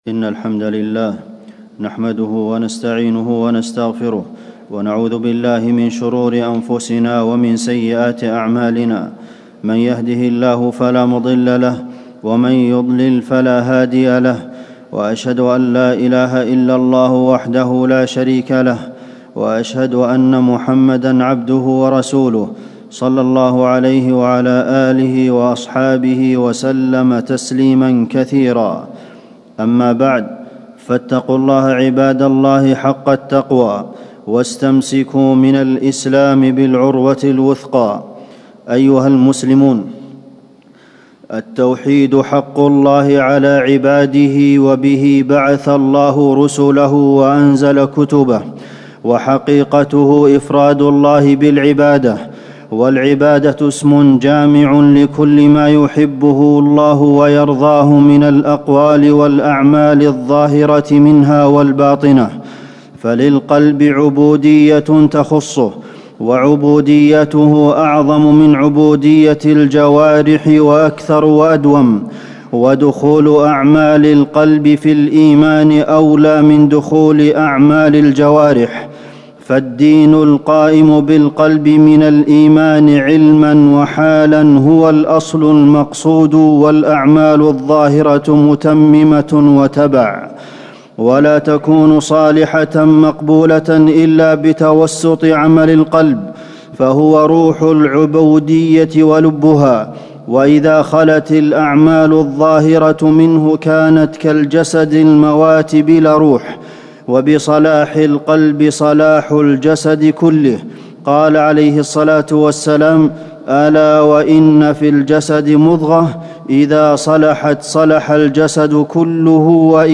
تاريخ النشر ١٨ ربيع الثاني ١٤٣٩ هـ المكان: المسجد النبوي الشيخ: فضيلة الشيخ د. عبدالمحسن بن محمد القاسم فضيلة الشيخ د. عبدالمحسن بن محمد القاسم حسن الظن بالله تعالى